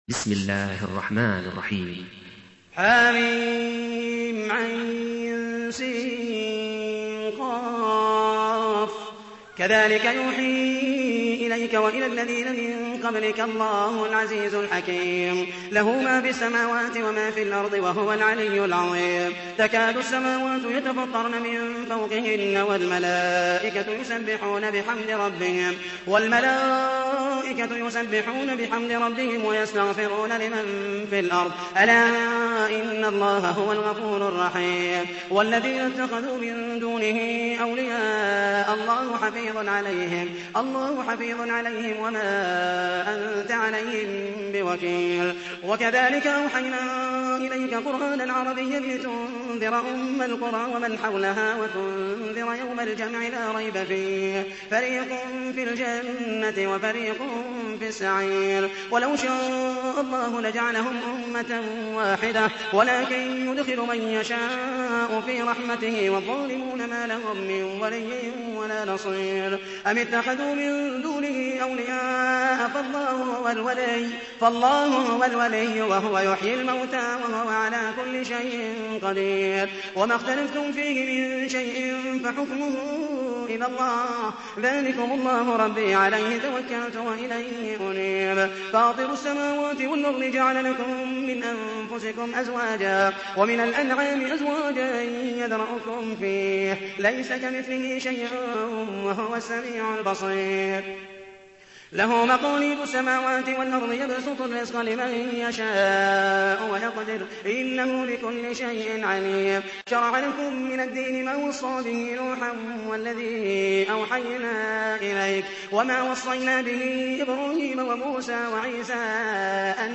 تحميل : 42. سورة الشورى / القارئ محمد المحيسني / القرآن الكريم / موقع يا حسين